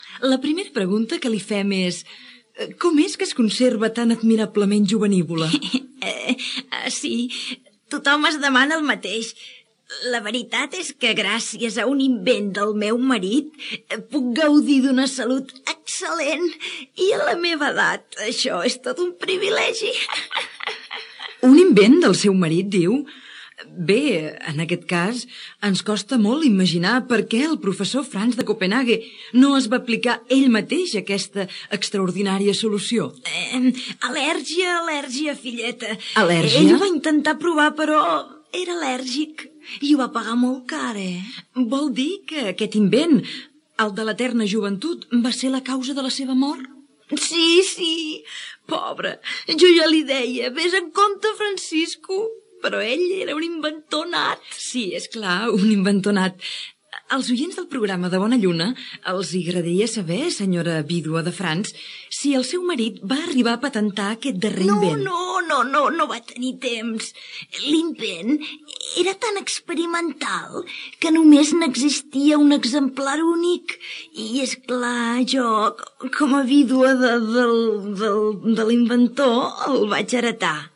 Entreteniment
Fragment extret del programa "Com sonava", emès per Ràdio 4 el 24 de setembre de 2016 i accessible al web RTVE Audio